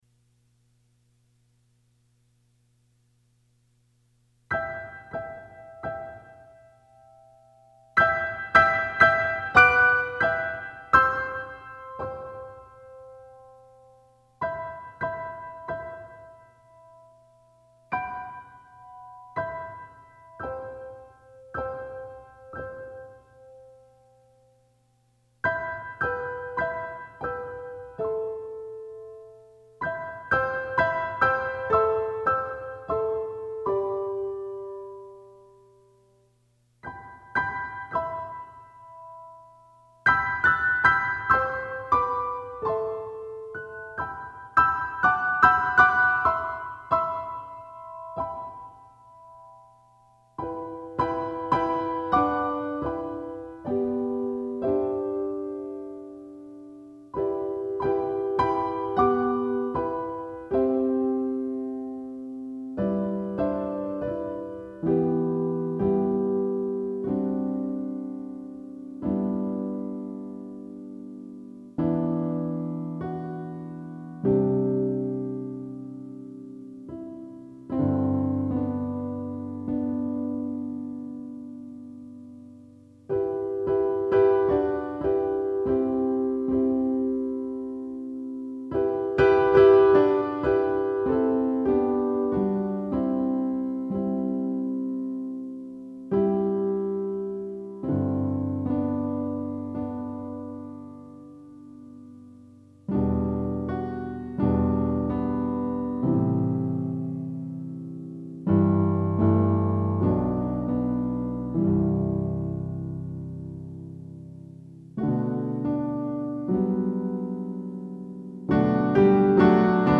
for SATB, Flute, Bb Clarinet, Cello, and Piano